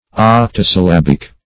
Octosyllabic \Oc`to*syl*lab"ic\, Octosyllabical